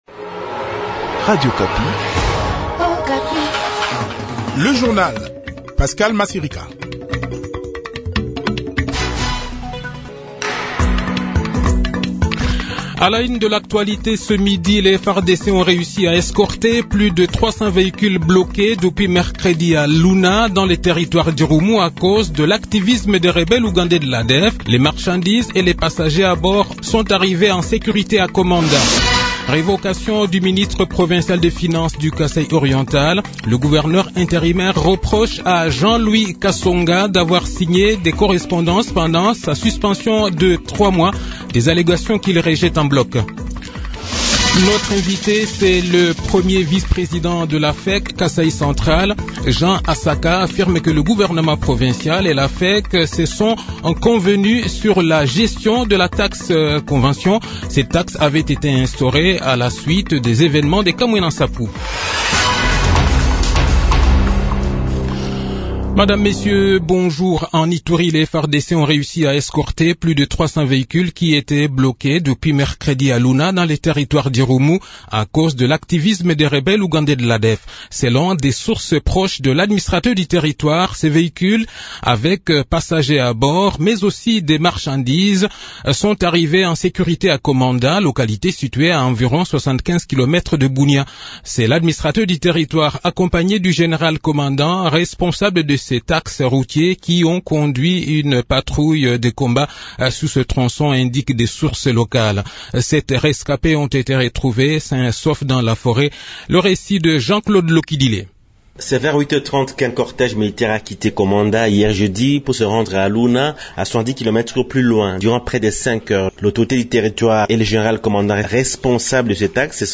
Le journal de 12 h, 3 Septembre 2021